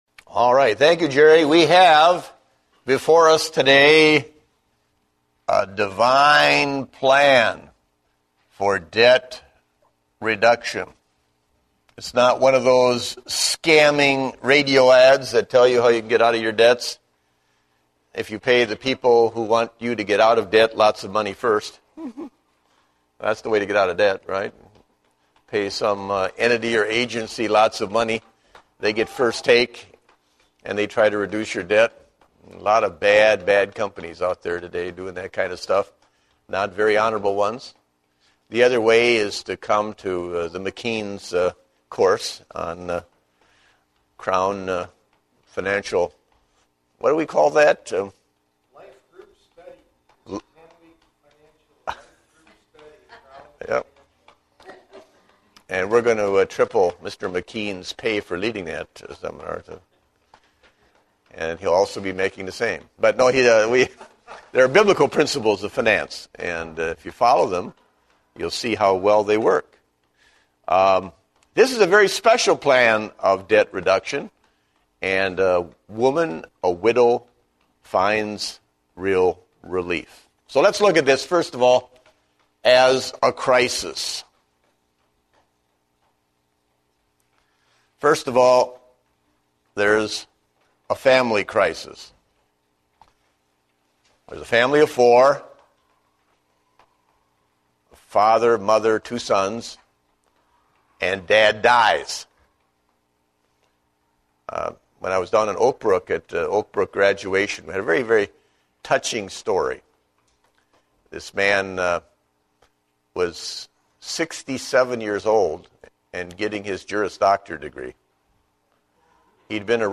Date: August 15, 2010 (Adult Sunday School)